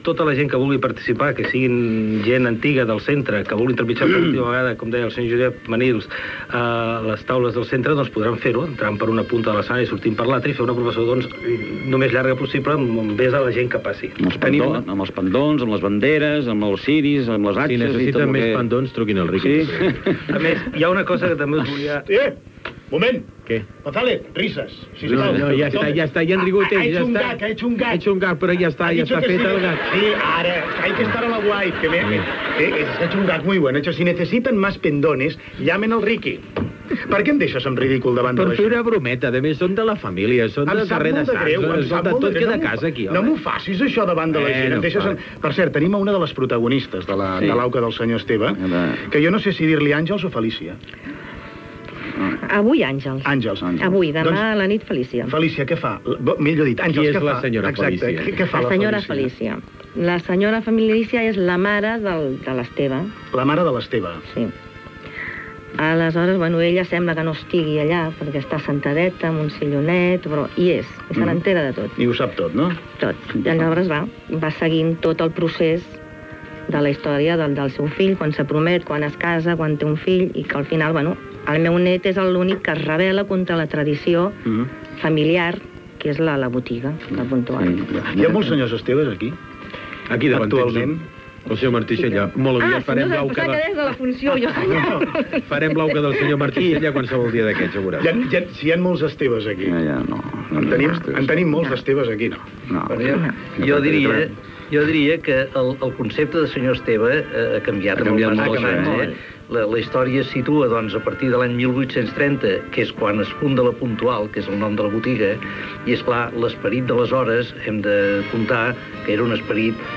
Indicatiu del programa. Publicitat.
Entreteniment